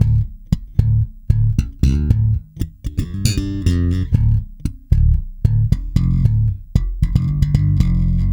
-JP THUMB D.wav